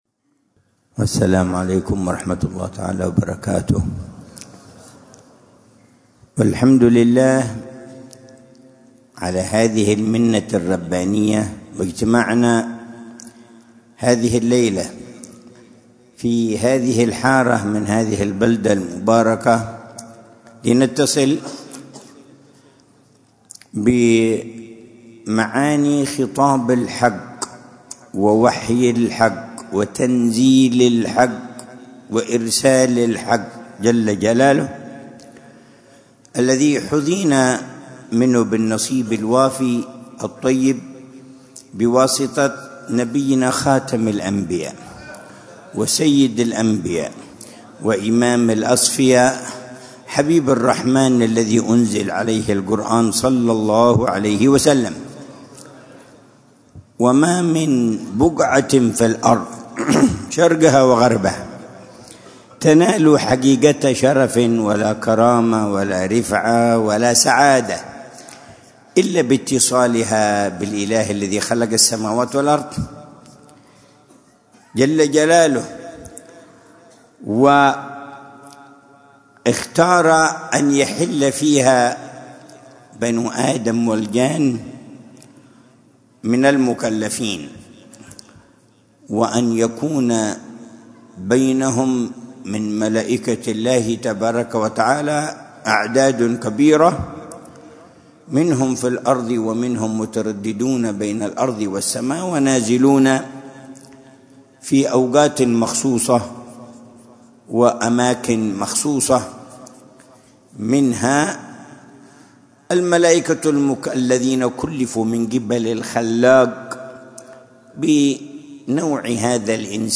محاضرة العلامة الحبيب عمر بن محمد بن حفيظ في جلسة الجمعة الشهرية الـ55، في مسجد الإمام حسين مولى خيلة، بحارة الرضيمة وخيلة، مدينة تريم، ليلة السبت 20 جمادى الآخرة 1446هـ، بعنوان: